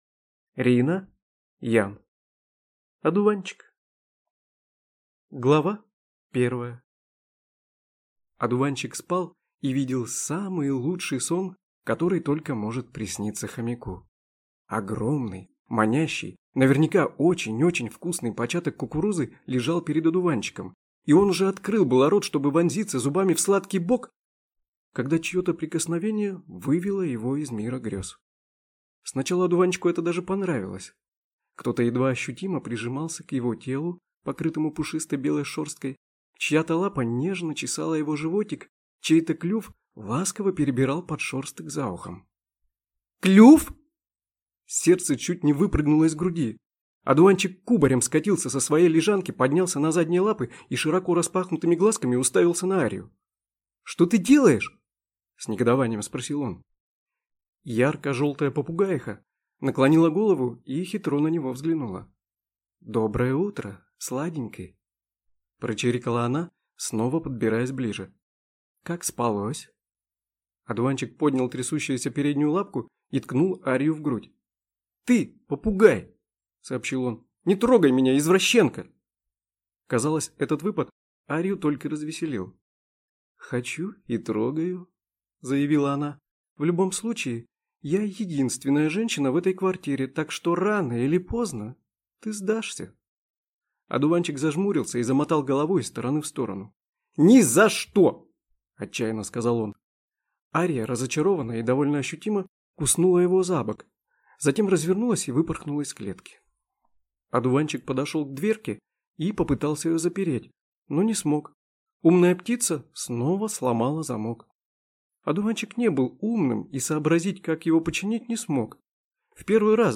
Аудиокнига Одуванчик | Библиотека аудиокниг